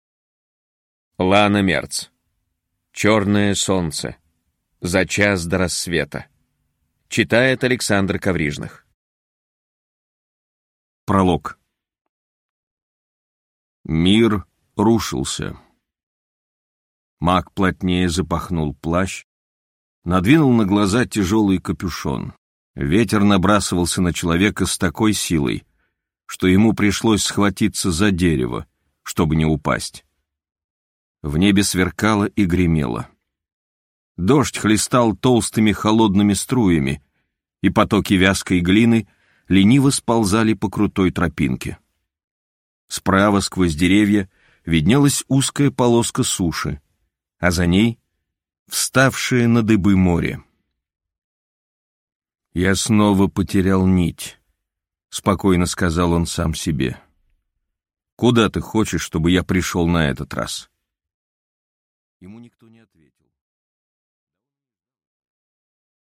Аудиокнига Чёрное солнце. За час до рассвета | Библиотека аудиокниг